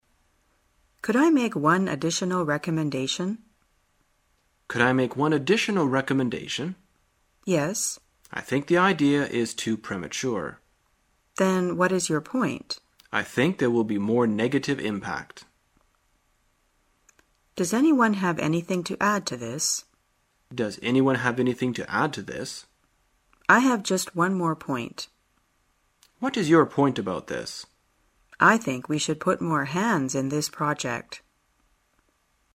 在线英语听力室生活口语天天说 第62期:怎样补充意见的听力文件下载,《生活口语天天说》栏目将日常生活中最常用到的口语句型进行收集和重点讲解。真人发音配字幕帮助英语爱好者们练习听力并进行口语跟读。